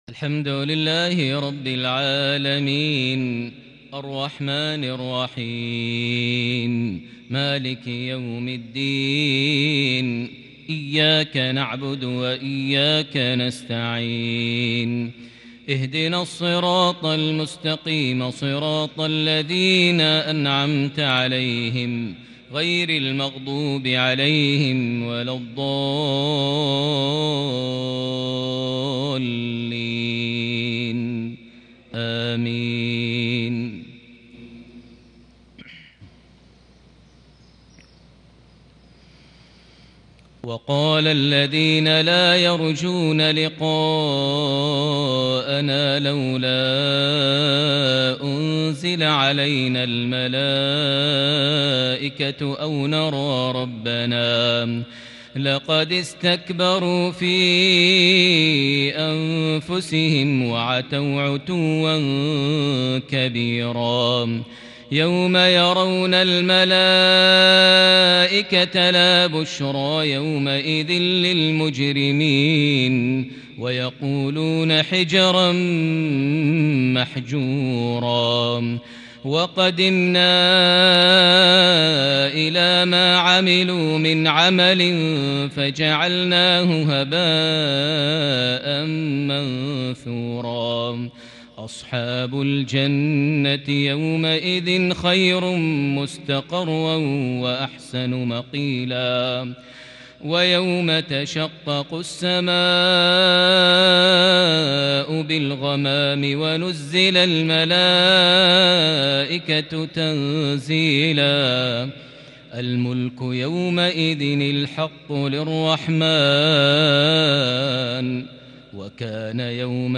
صلاة الفجر من سورة الفرقان الثلاثاء 20 محرم 1442هـ |Surah Al-Furqan 8-9-2020 prayer from > 1442 🕋 > الفروض - تلاوات الحرمين